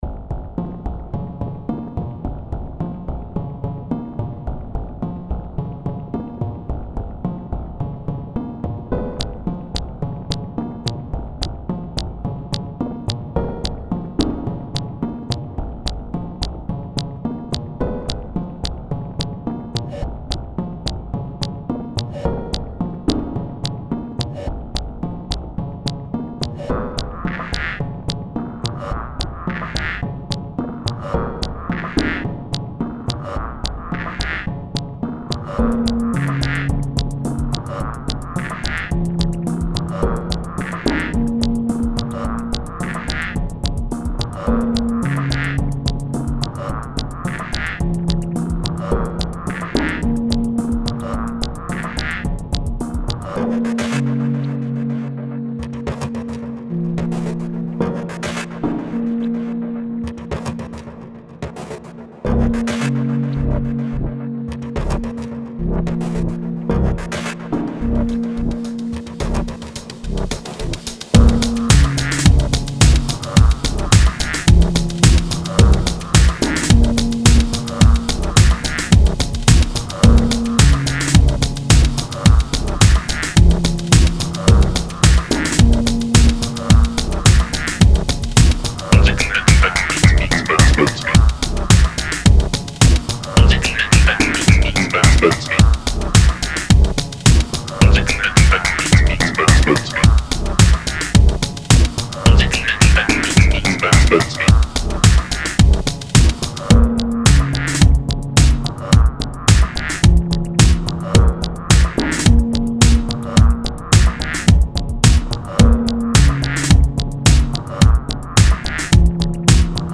I like the panning blurbly sound
IDM